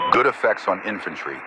Added "infantry killed" radio messages
pilotKillInfantry3.ogg